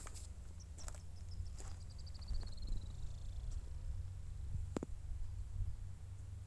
Asthenes dorbignyi
Nome em Inglês: Rusty-vented Canastero
Condição: Selvagem
Certeza: Fotografado, Gravado Vocal